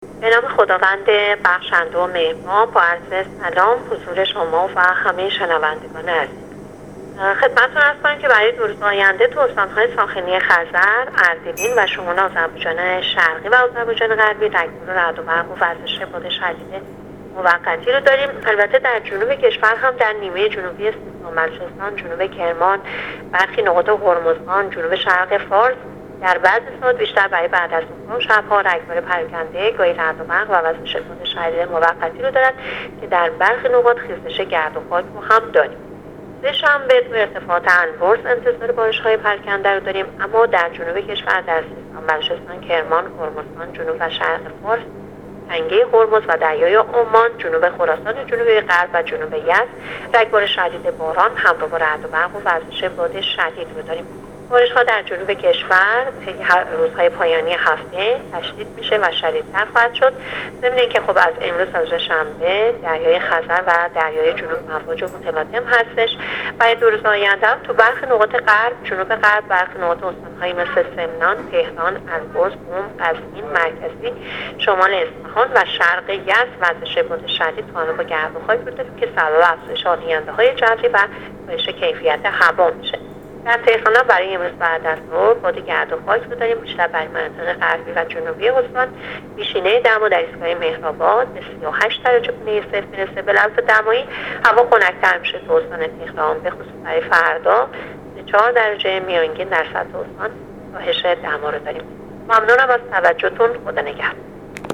گزارش رادیو اینترنتی پایگاه خبری از آخرین وضعیت آب‌وهوای دوم مرداد ماه؛